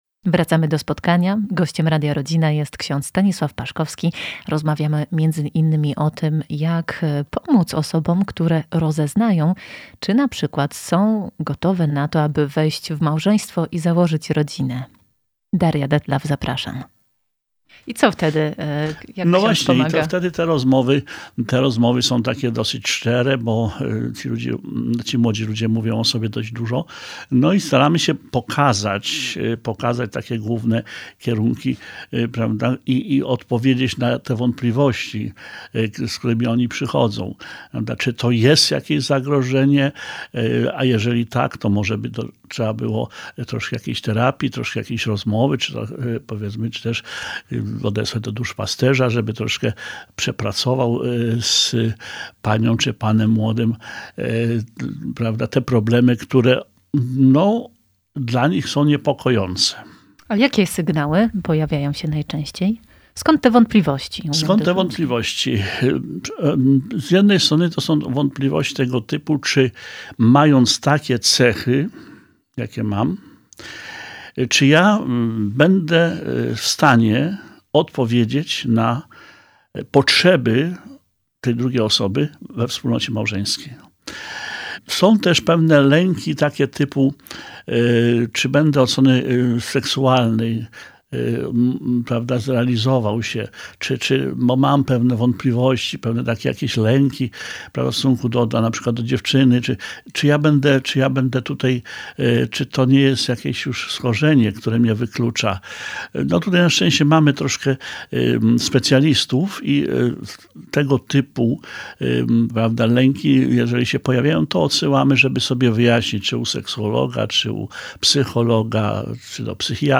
W jednej z ostatnich audycji rozmawialiśmy o towarzyszeniu rodzinom, o świętości i ochronie życia od poczęcia do momentu naturalnej śmierci [marzec 2021].